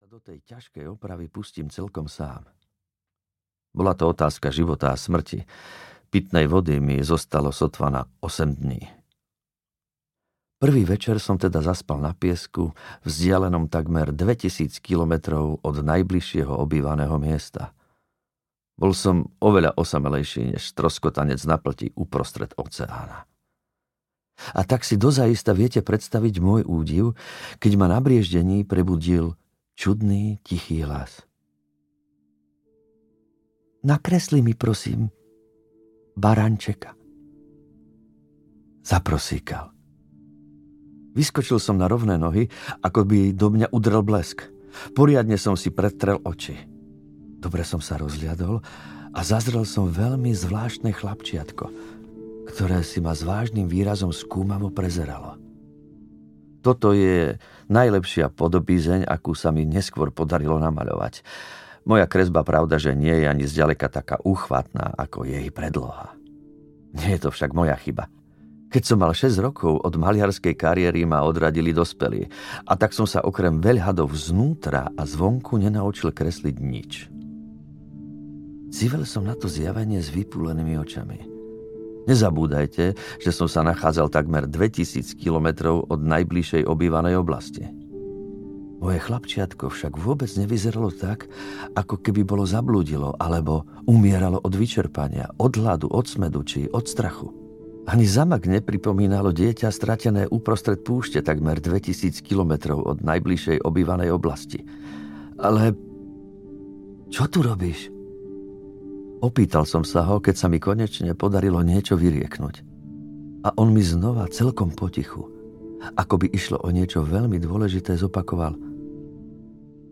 Malý princ audiokniha
Ukázka z knihy
• InterpretAdy Hajdu